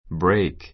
bréik ブ レ イ ク